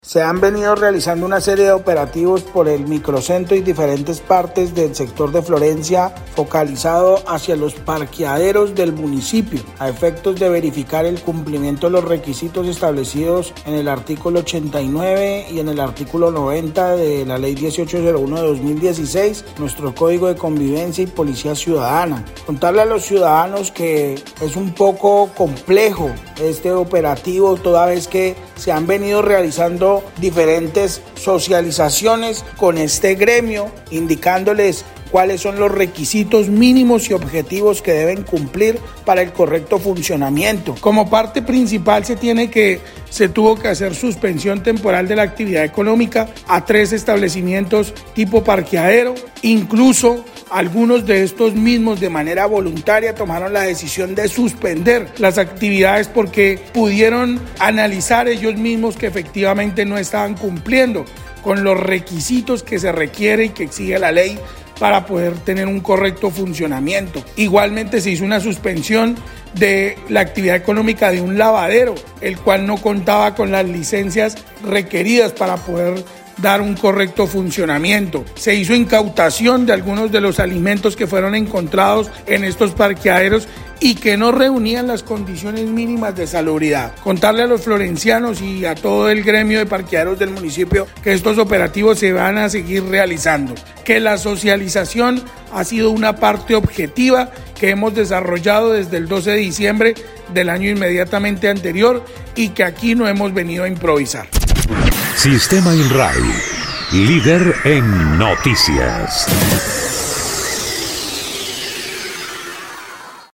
Jaime Becerra, secretario de gobierno municipal, explicó que estos establecimientos comerciales ya habían sido advertidos por esta irregularidad desde el año anterior, cuando se les indicó que no cumplían con la Ley 1801 de 2016 o código de convivencia y policía ciudadana, en sus artículos 89 y 90.